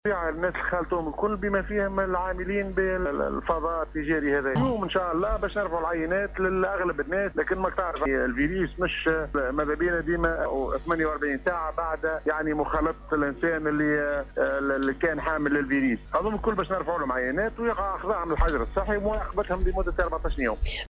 اكد المدير الجهوي للصحة بالقصرين الدكتور عبد الغني الشعباني في تصريح اعلامي لراديو سيليوم اف ام ، ان الايفواريين الاربعة الذين اثبتت التحاليل اصابتهم بفيروس كورونا ، تم وضعهم في الحجر الصحي بدار الشباب القصرين في مرحلة اولى ، ثم تم تحويلهم مساء امس الجمعة 19 جوان 2020 بواسطة سيارة إسعاف الى مركز  covid-19  بالمنستير لتلقي العلاج.